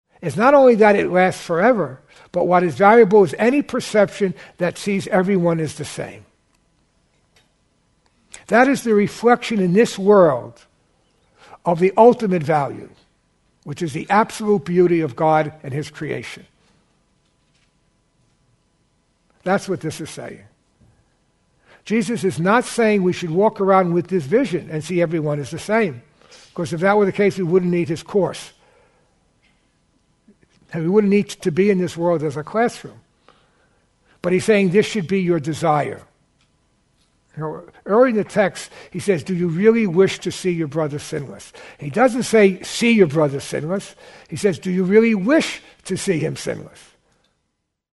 This May 2012 workshop uses Plato’s discussion of beauty as the springboard for considering the Course’s important distinction between form and content, body and mind. An important focus of the workshop is the metaphor of ascending from the darkness of illusion to the light of truth, a primary theme running through all of Plato, as it does in A Course in Miracles.